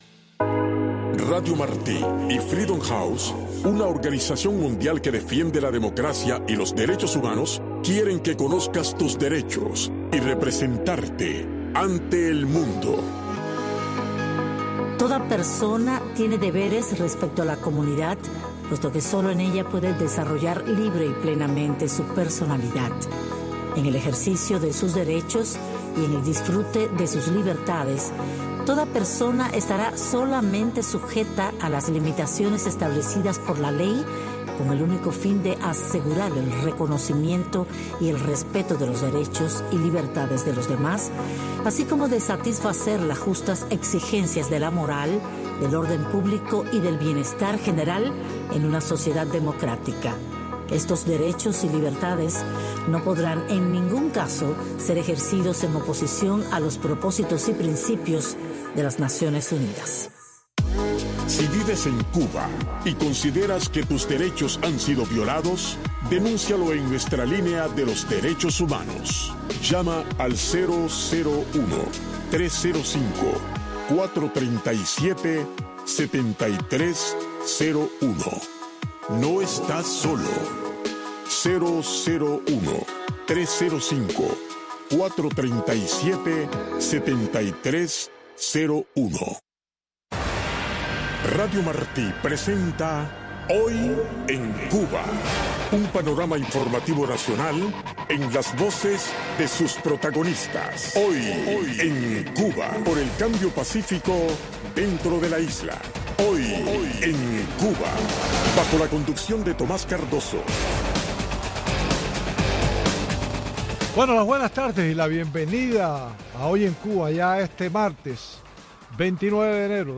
Un espacio informativo con énfasis noticioso en vivo donde se intenta ofrecer un variado flujo de información sobre Cuba, tanto desde la isla, así como desde el exterior.